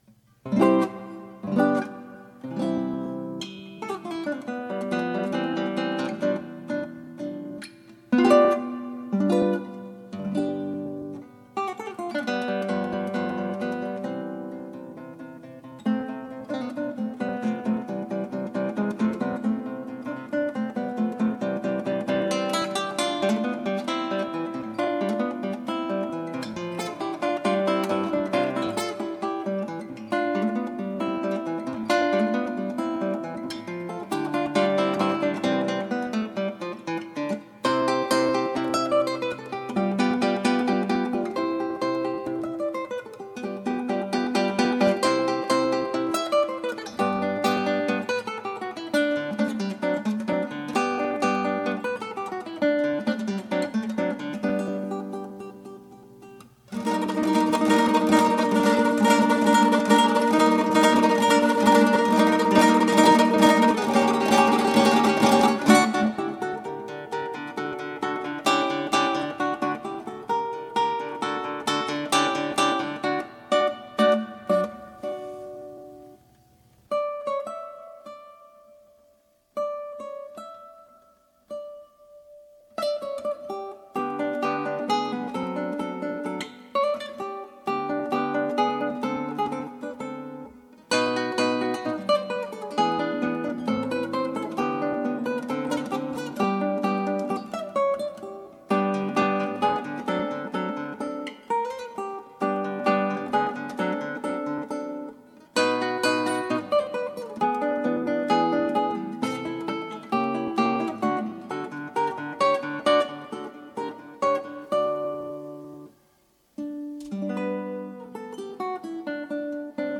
クラシックギター　ストリーミング　コンサート
出来たような出来てないような演奏です。